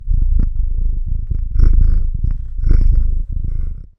Cat Purring
A content cat purring steadily with a warm, rhythmic rumble and occasional soft meow
cat-purring.mp3